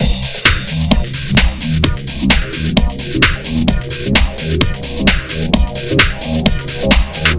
130 Strictly Rhythm.wav